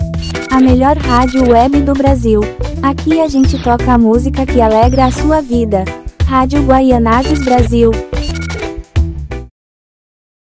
Feminina4IA.mp3